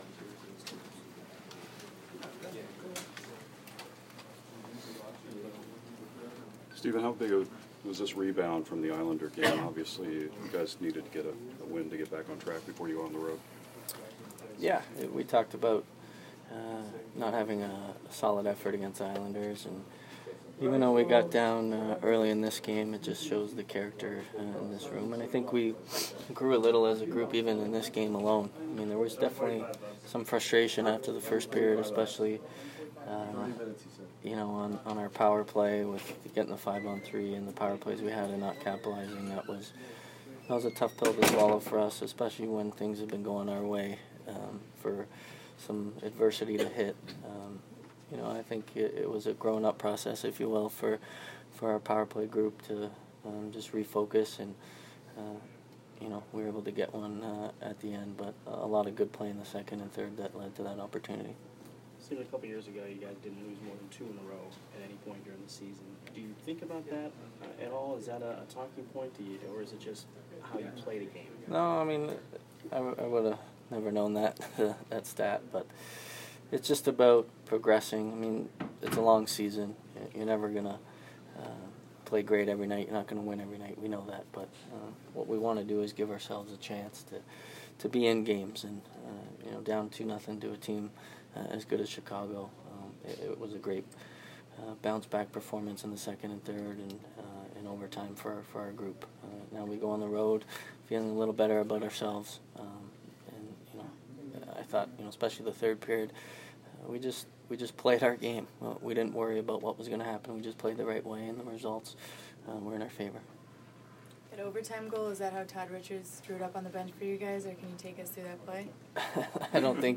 Steven Stamkos Post-Game 11/22